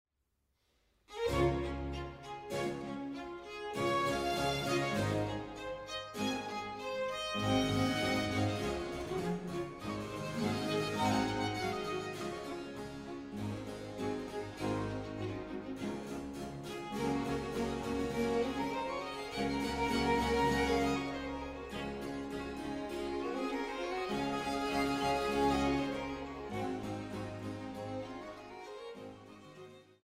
Sopran
Cembalo und musikalische Leitung
Ensemble für Alte Musik